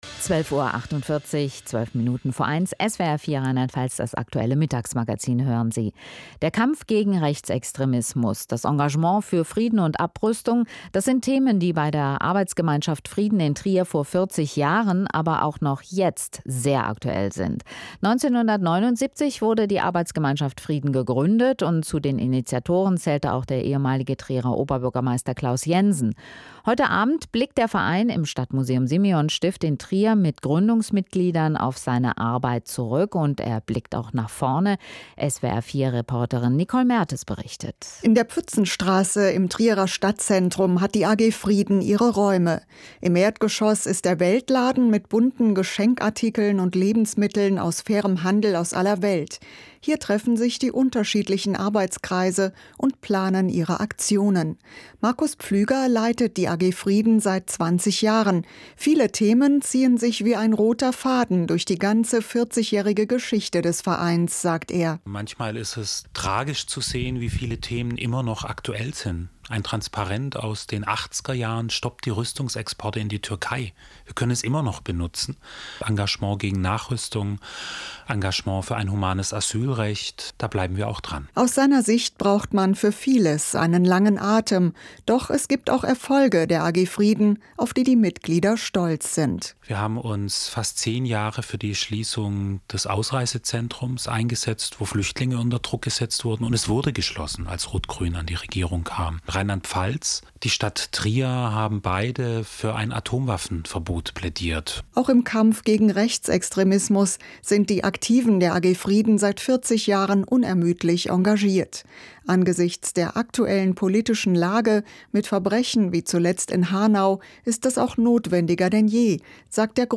Radiobeitrag im SWR 4 vom 06.03.2020